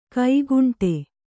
♪ kaiguṇṭe